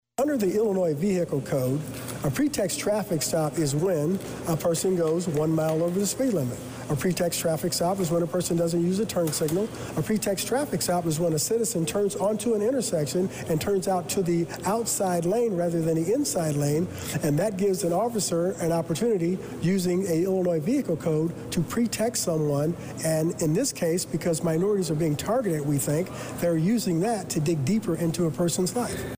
Randle stated before the meeting that the main problem is he sees Danville Police using what is known as “pre text traffic stops” to pull a vehicle over; and then using that as an excuse to “search the vehicle just in case they find something.”